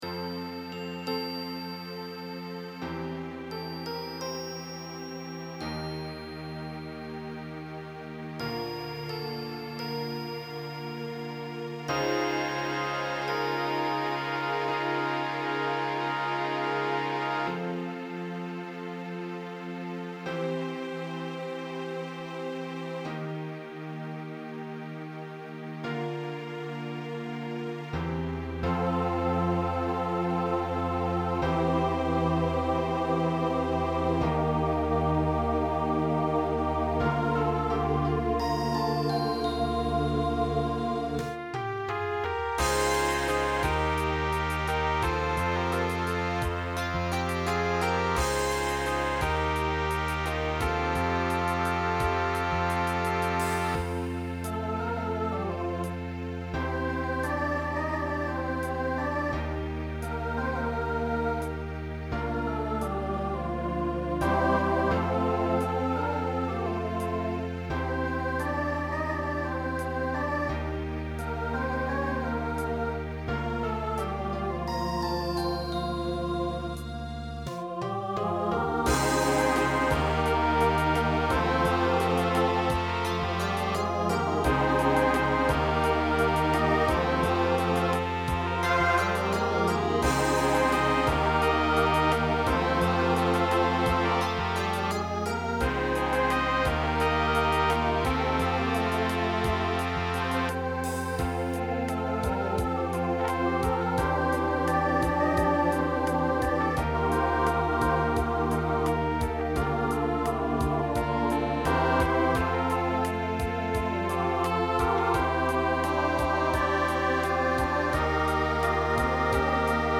Voicing SATB Instrumental combo Genre Pop/Dance
Function Ballad